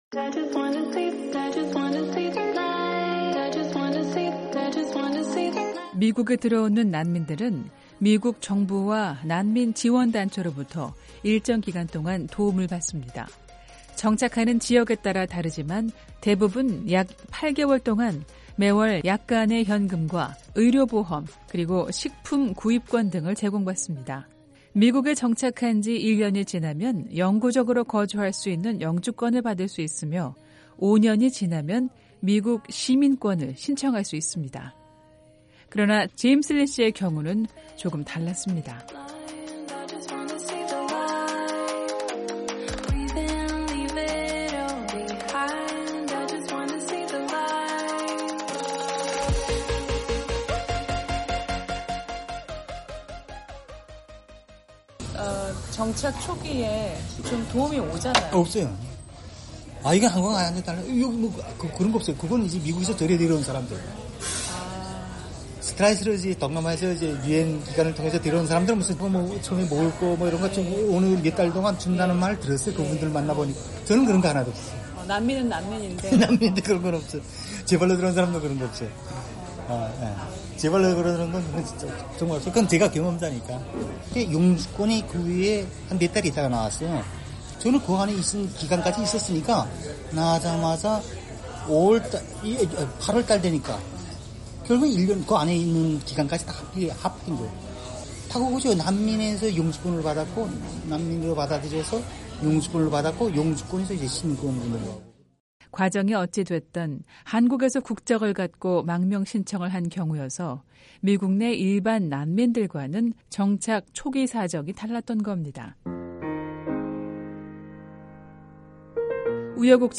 VOA 한국어 TV 프로그램 VOA 한국어 라디오 프로그램